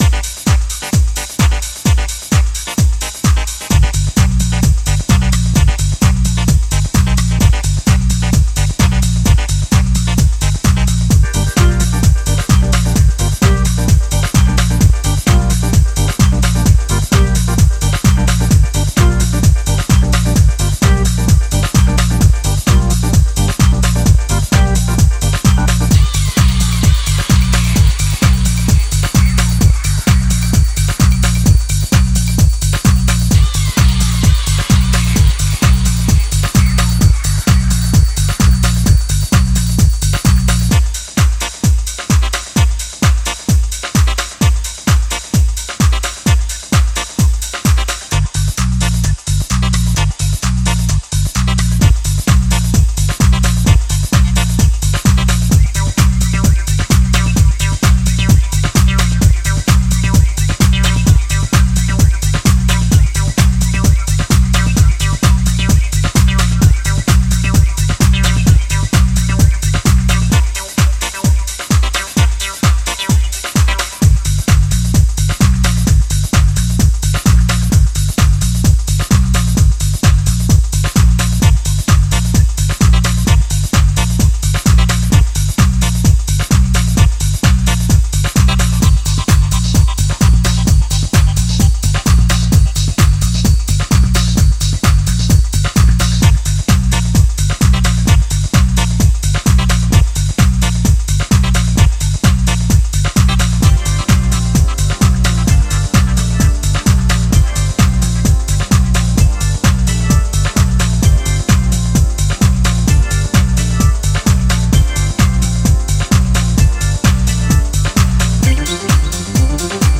催眠的なミニマルベースにアップリフティングなオルガンリフとアシッドを絡めてファンキーに跳ねる